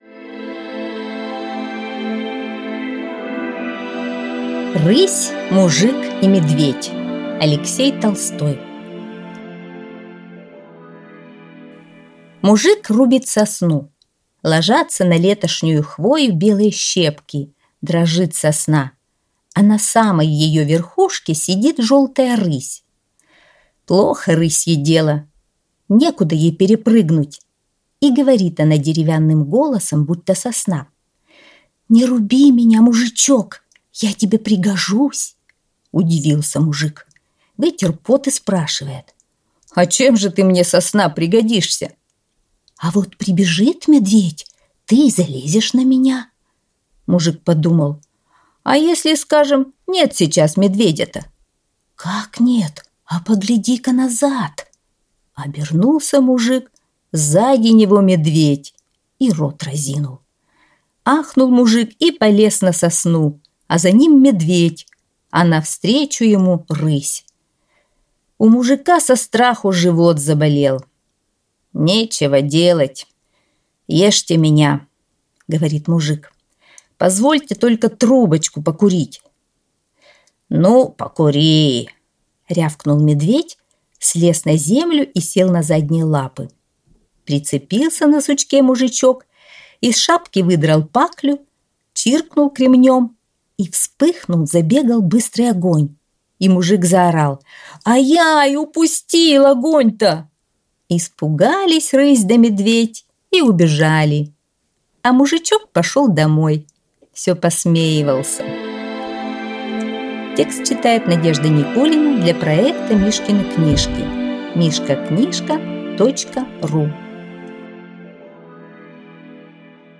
Рысь, мужик и медведь - аудиосказка Алексея Толстого - слушать онлайн | Мишкины книжки
Аудиокнига в разделах